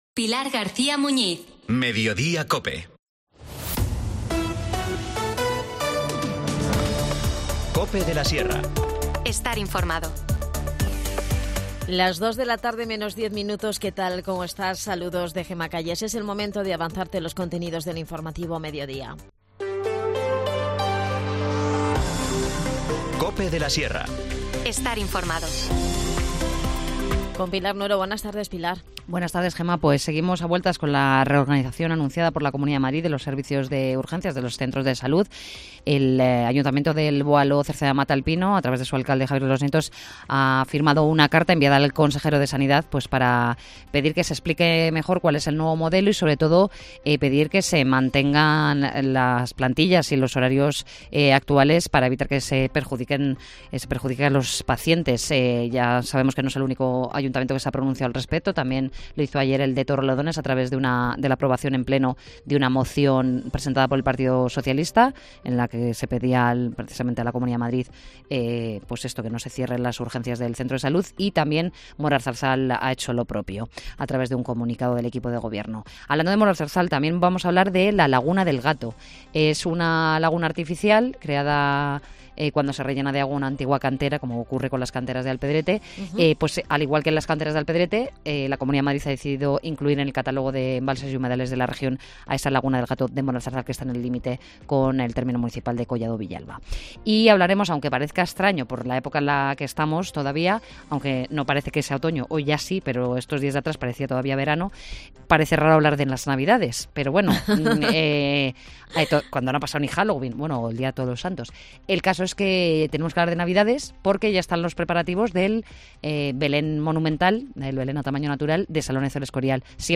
ilustrador, narrador y animador de lectura sobre esta iniciativa en la que los jóvenes se encargan de todo el proceso audiovisual.